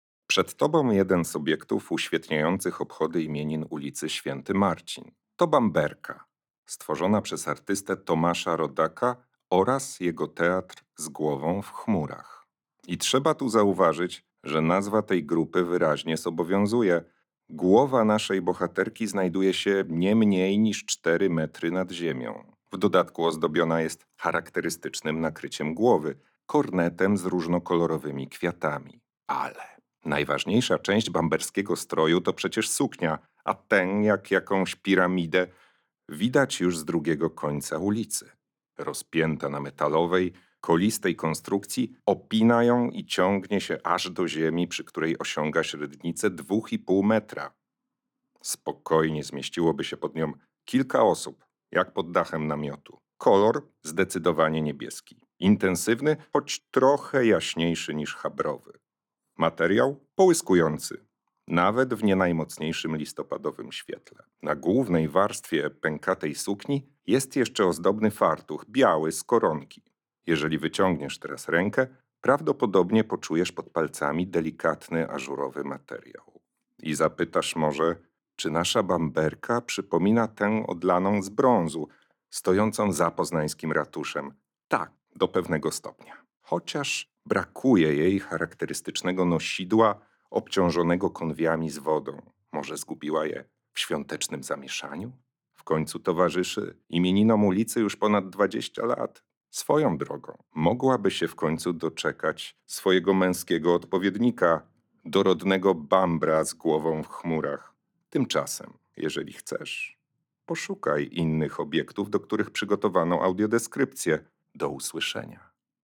Bamberki – audiodeskrypcja